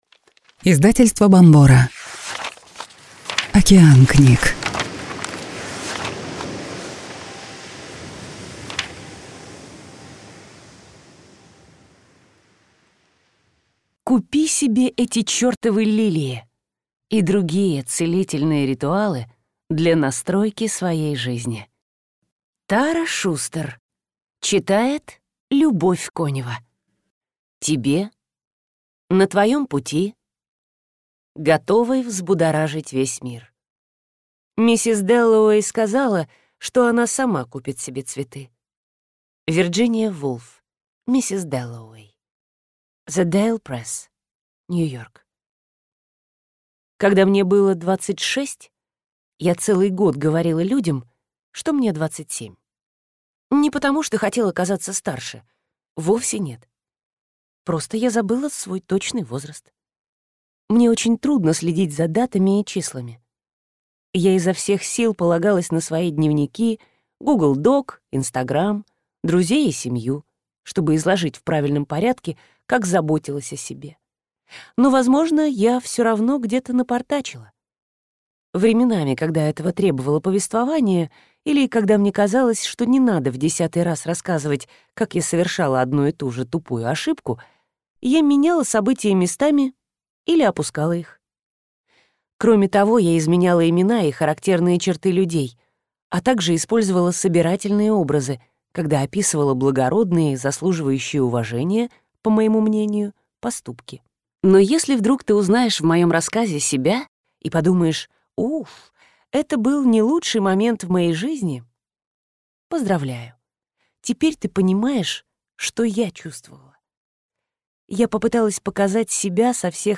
Аудиокнига Купи себе эти чертовы лилии. И другие целительные ритуалы для настройки своей жизни | Библиотека аудиокниг